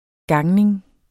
Udtale [ ˈgɑŋneŋ ]